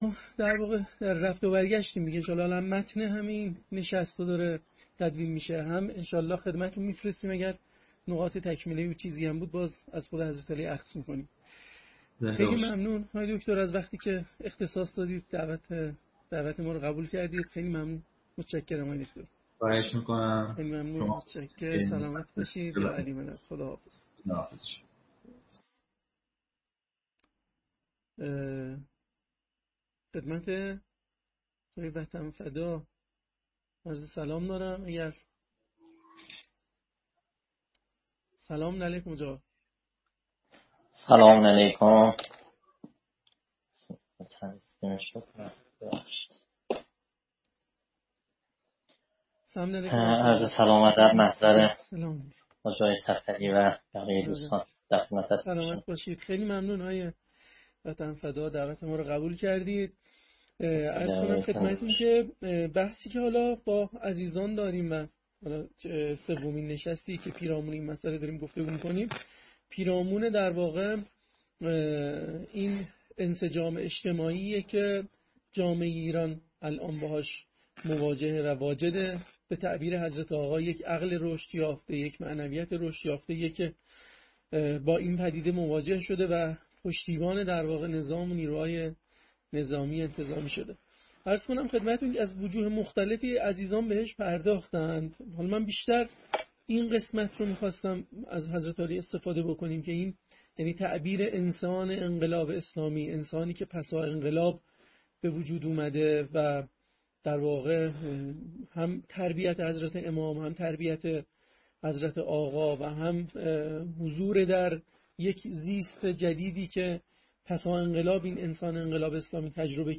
پرسش مخاطبین: * موضوع بحث درباره انسجام اجتماعی جامعه ایران است که به تعبیر رهبری، نتیجه عقلانیت و معنویت رشد یافته در مردم است؛ انسجامی که پشتیبان نظام و نیروهای مسلح شده است.